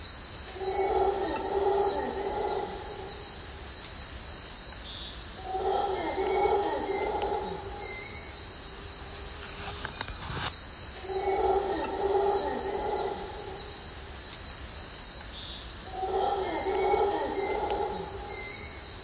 Not everything in the rainforest is seen, today provided the perfect example when deep into the walk we heard some commotion deep in the forest somewhere up the hill above us. A couple of people suggested that we were listening to howler monkeys (genus Alouatta), what do you think.
Mammals: White-nosed Coati and heard Howler Monkeys